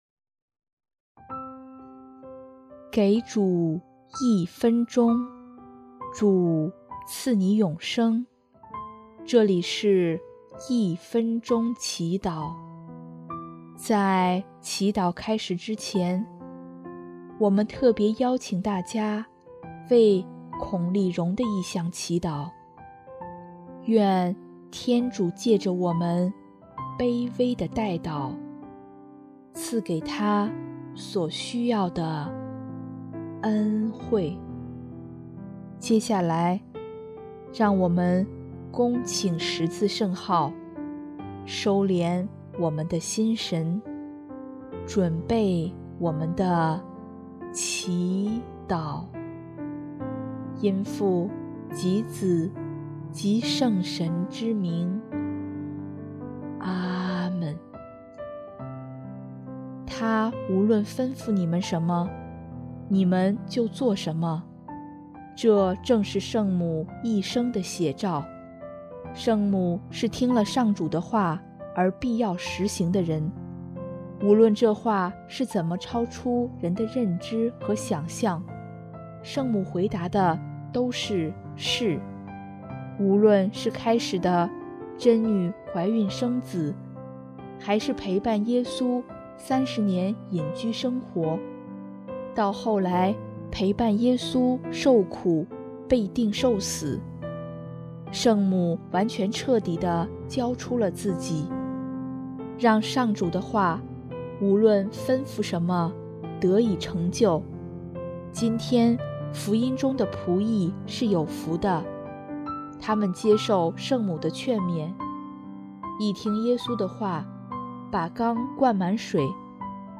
【一分钟祈祷】|1月19日 谦卑委顺的人是有福的